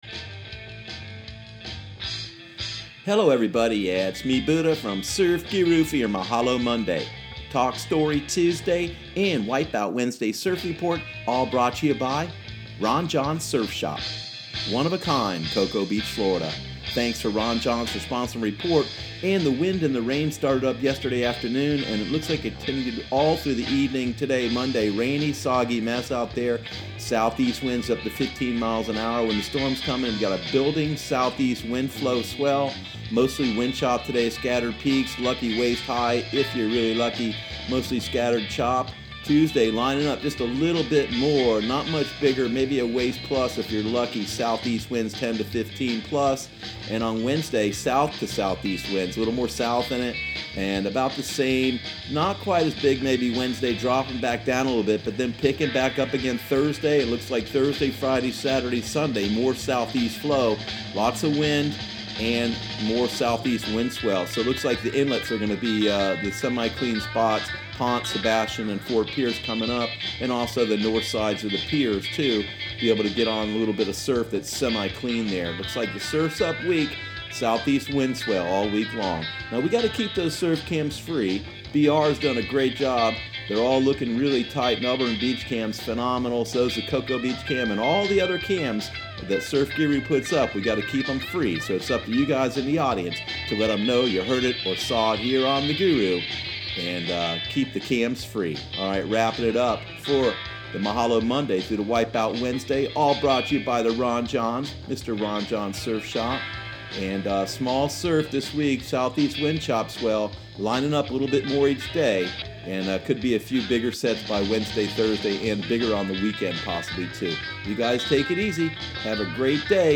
Surf Guru Surf Report and Forecast 05/14/2018 Audio surf report and surf forecast on May 14 for Central Florida and the Southeast.